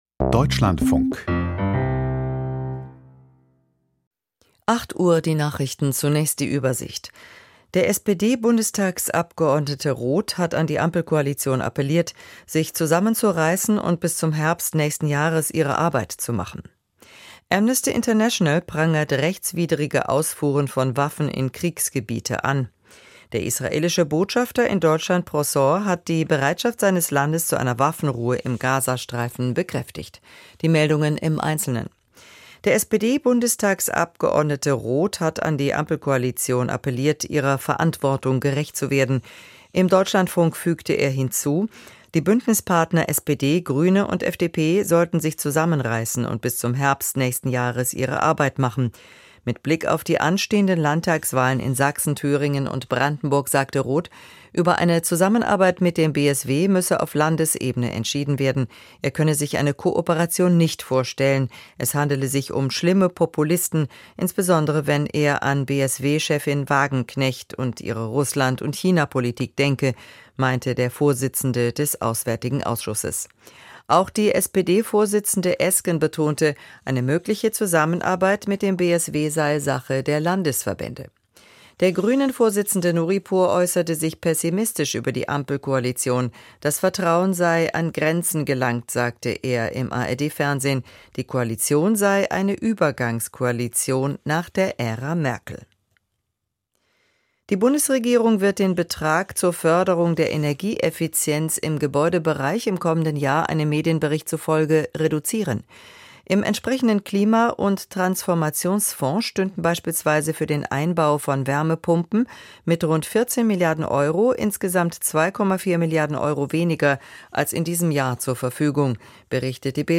Interview mit Michael Roth, SPD, VS Auswärtiger Ausschuss, zu: Ukraine und Gaza - 19.08.2024